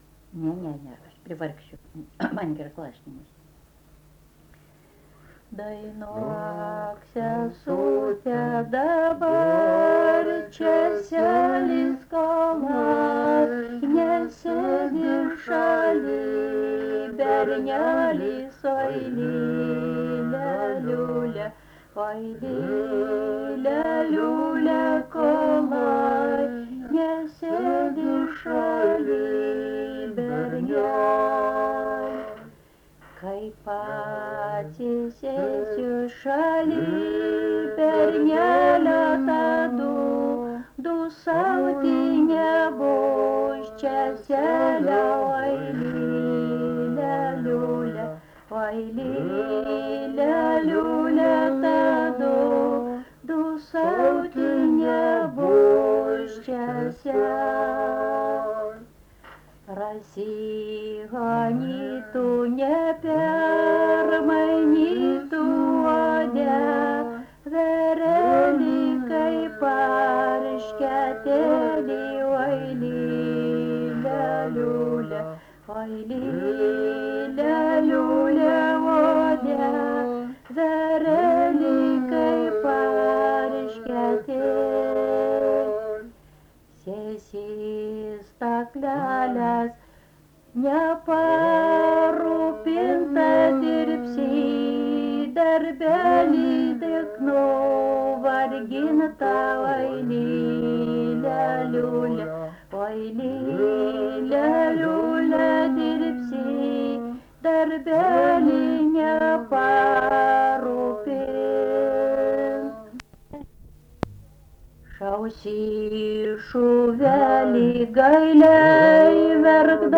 daina
Antanašė
vokalinis